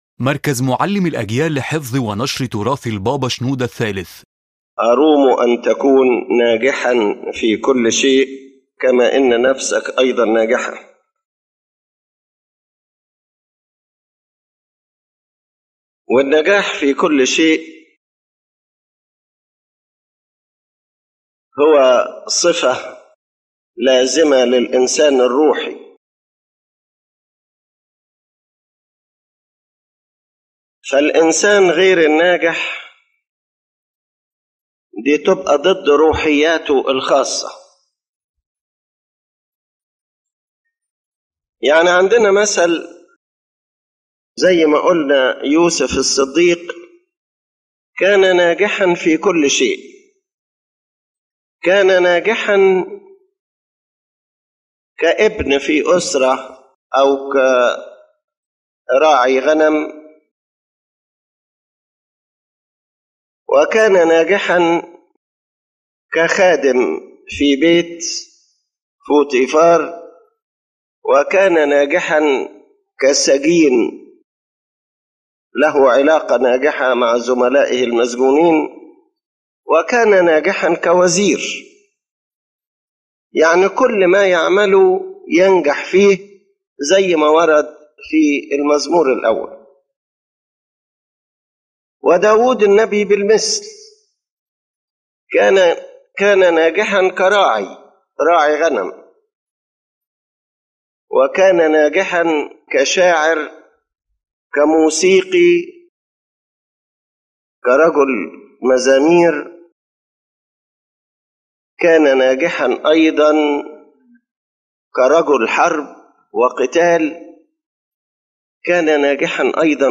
The lecture explains that true success is not merely external achievements, but an inner spiritual success that begins with a person’s relationship with God and reflects on all aspects of life.